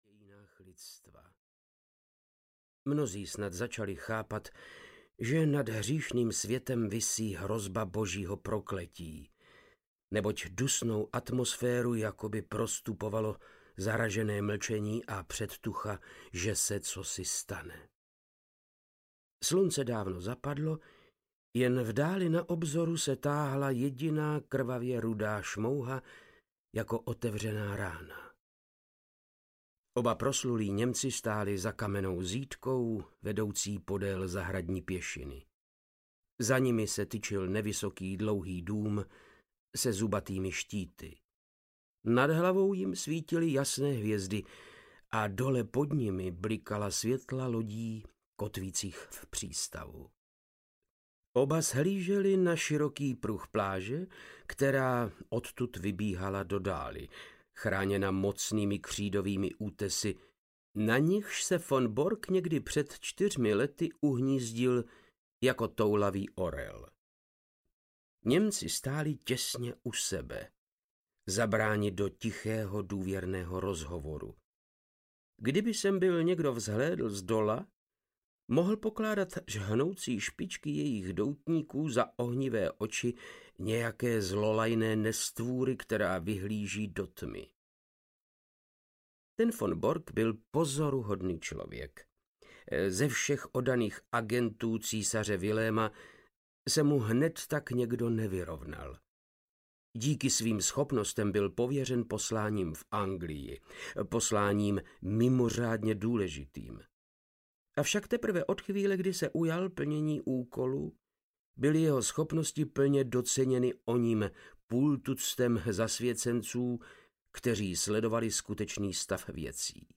Poslední poklona audiokniha
Ukázka z knihy
• InterpretVáclav Knop